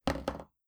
Impacts
clamour8.wav